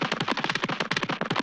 1 channel
fastrun.wav